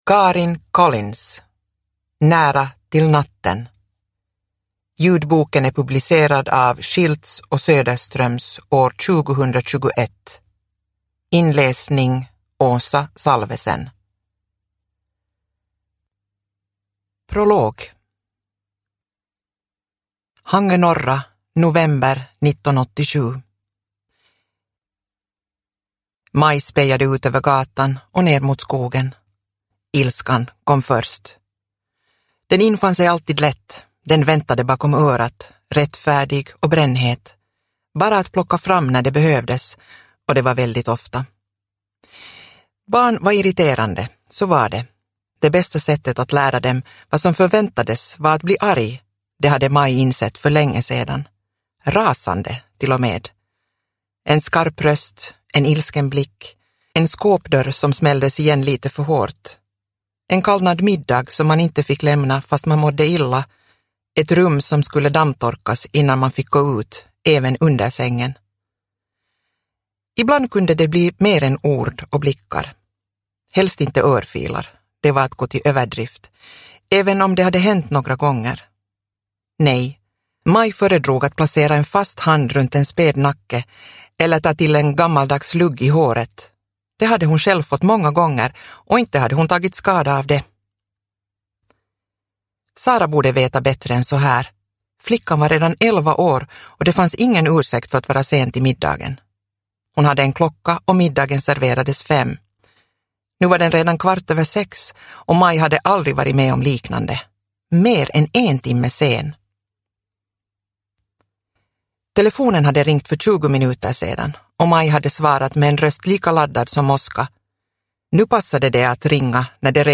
Nära till natten – Ljudbok – Laddas ner